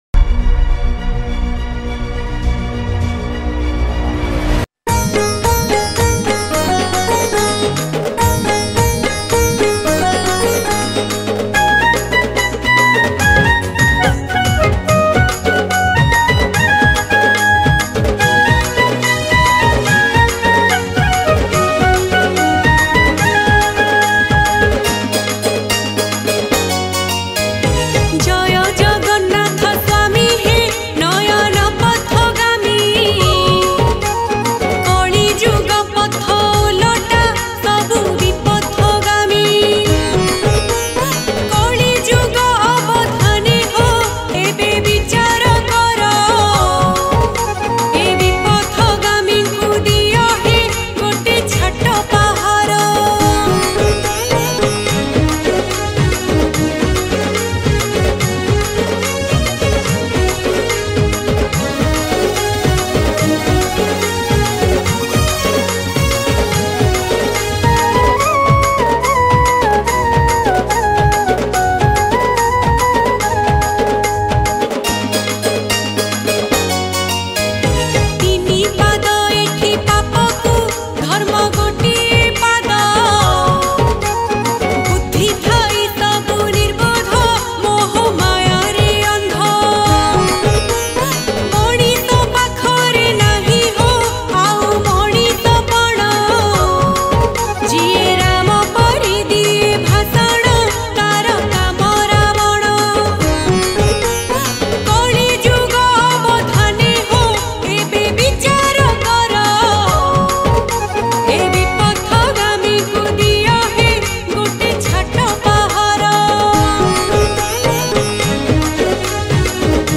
Ratha Yatra Odia Bhajan 2023 Songs Download
Keyboard